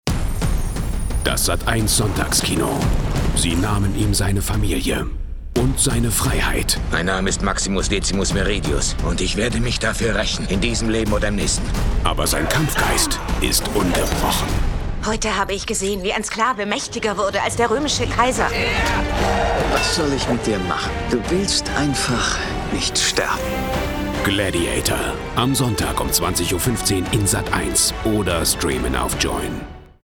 dunkel, sonor, souverän, markant
Station Voice